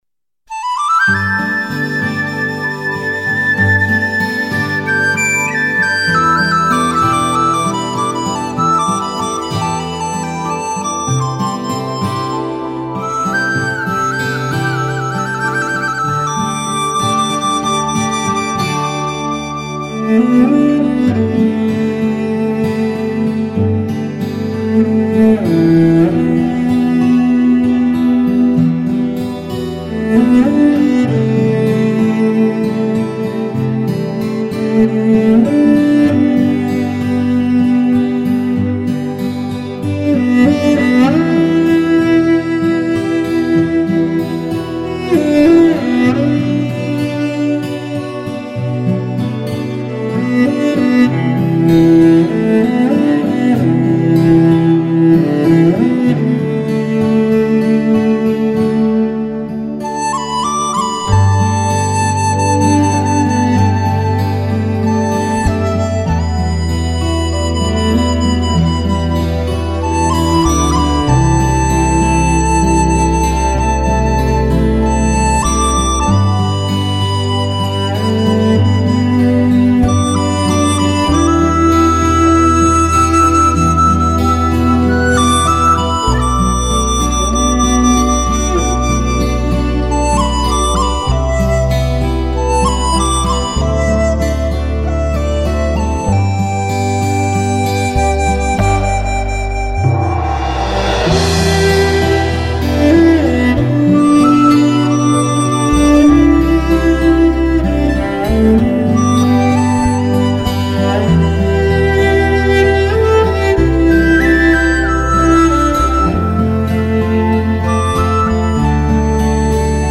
相信大家听到音乐的时候都感受到了内省的宁静和生活的不易。